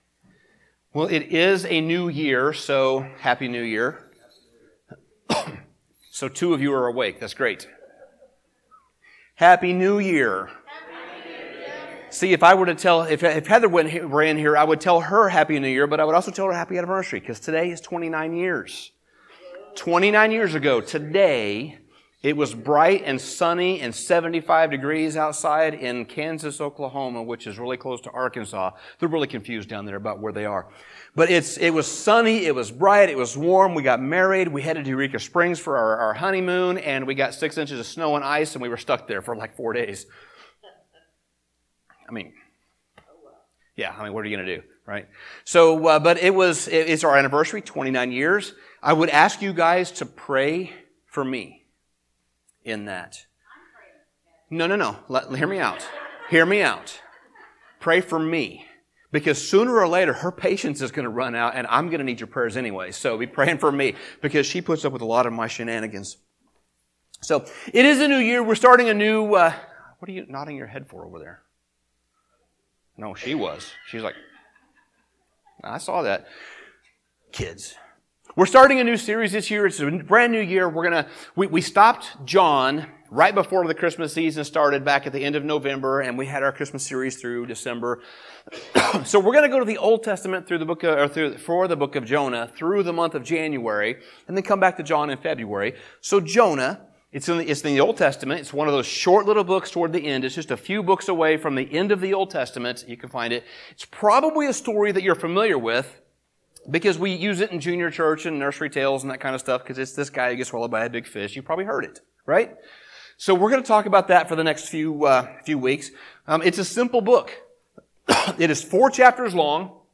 Sermon Summary The book of Jonah is centered around four different settings or scenes.